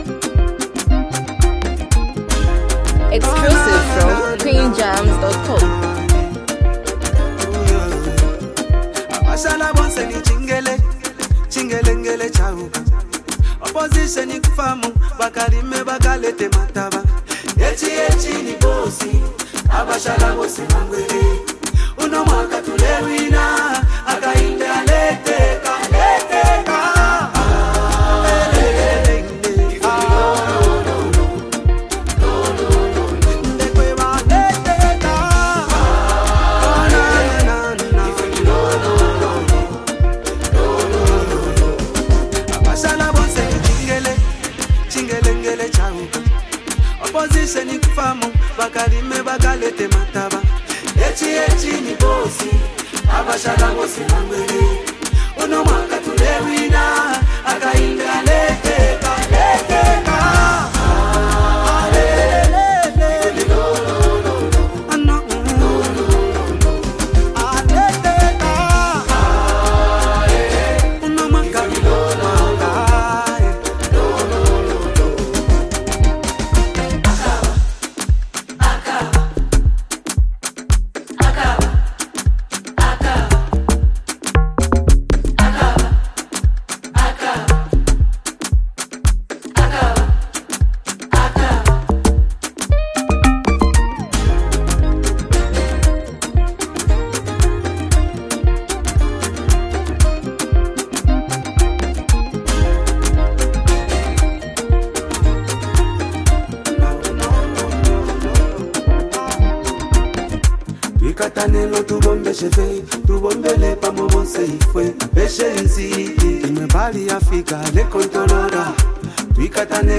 campaign song